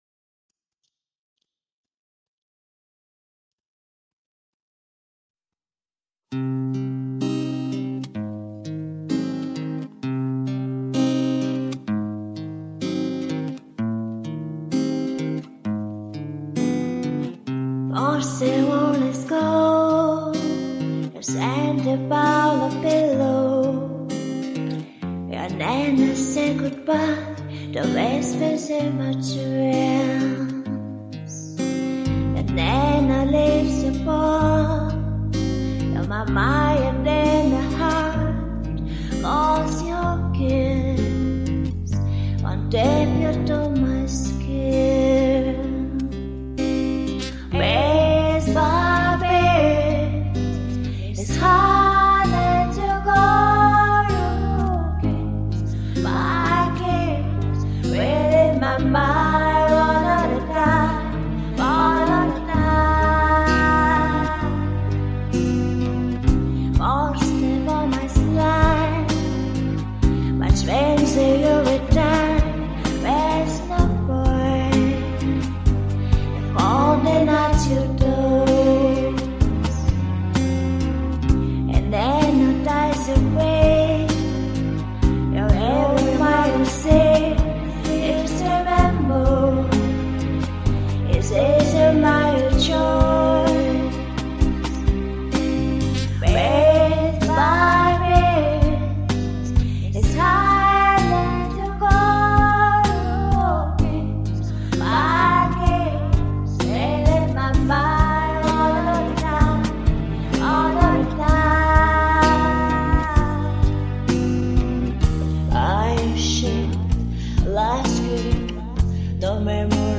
Вокализ был неопределённый....